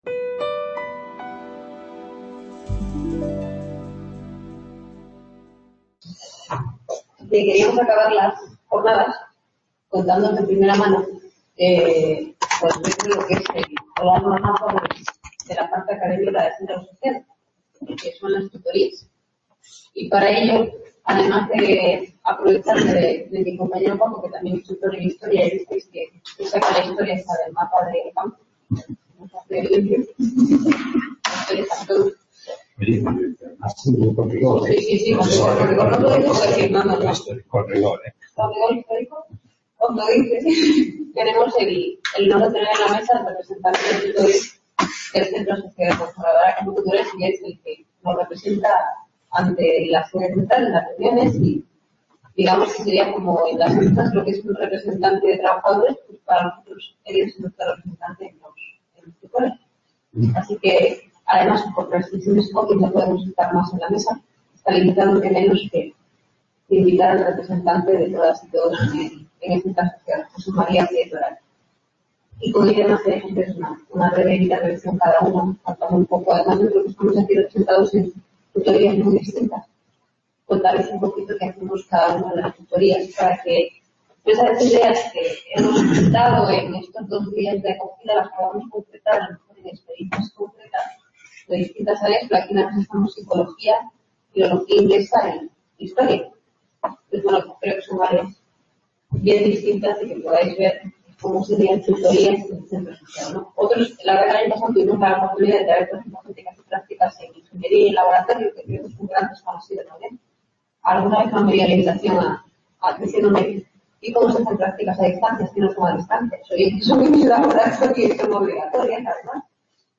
Mesa Redonda de Profesores/as tutores/as UNED | Repositorio Digital